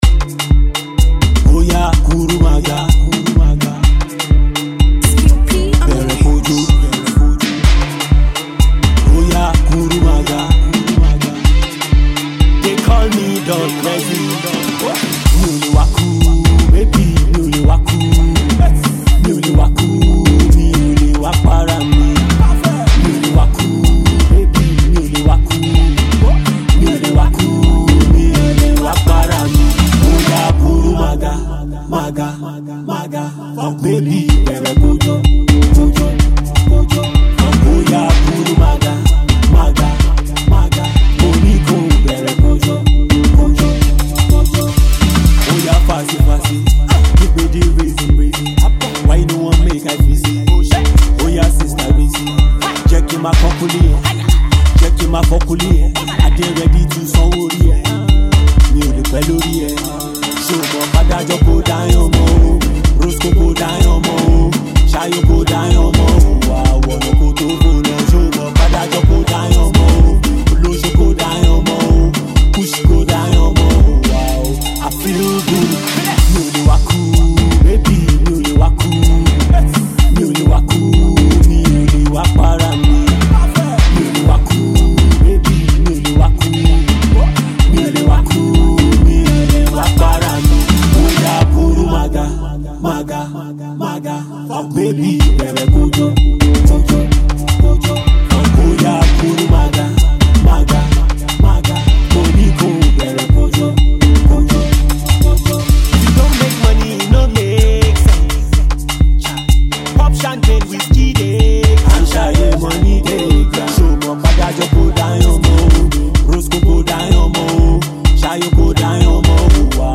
a Street Pop single
and highly repetitive